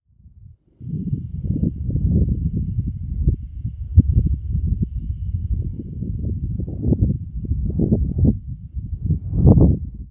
風,登山,山頂,暴風,強風,自然,４５３
効果音自然野外